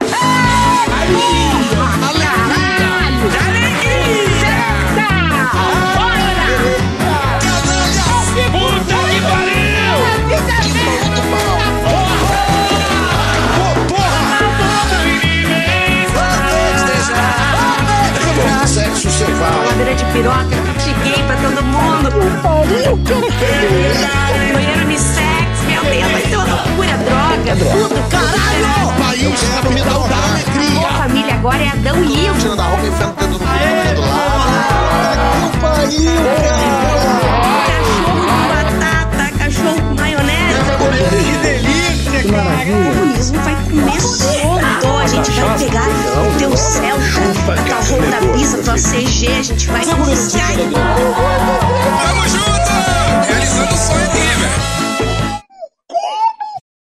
Compilação de memes do medo e delíria em brasília ao som de um hino que esqueci qual tocado num ritmo de festa. Tem Bolsonaro puto, Lula falando "chupa que a cana é doce", um caos completo.